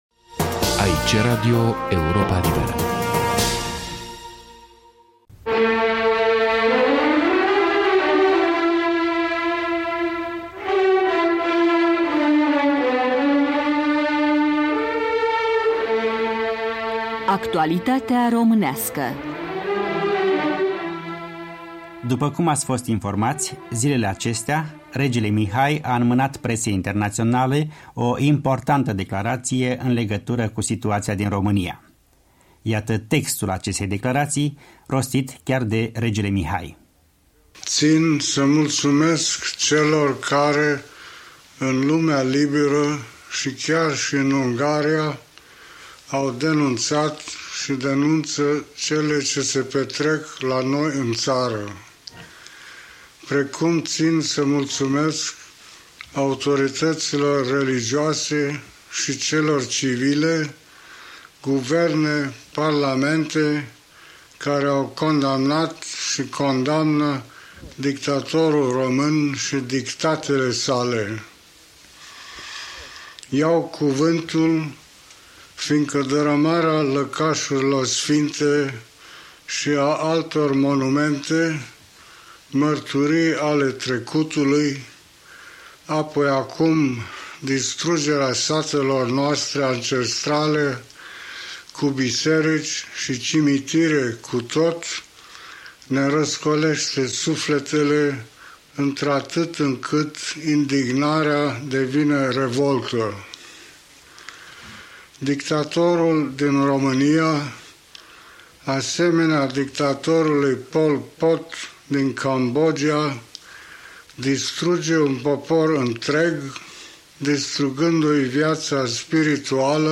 „Actualitatea românească”, 21 septembrie 1988, o declarație și un apel al Regelui Mihai în legătură cu situația dezastruoasă din România și cu distrugerea satelor.